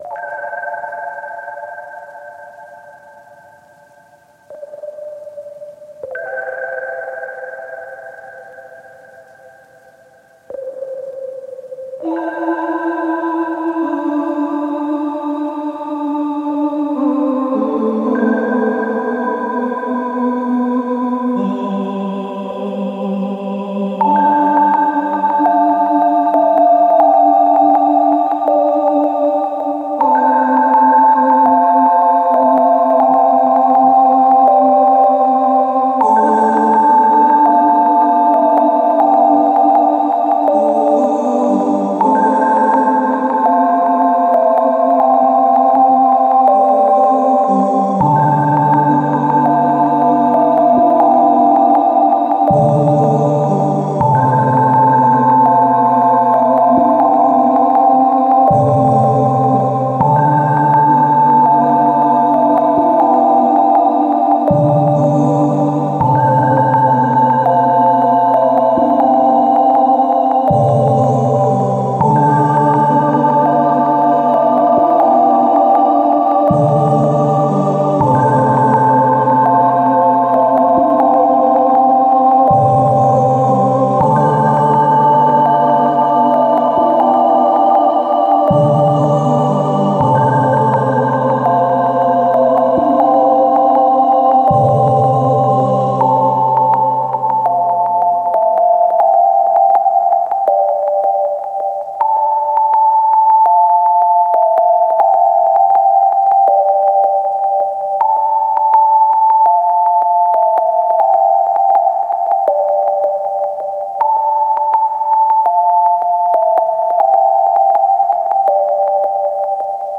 BGM
ファンタジーロング明るい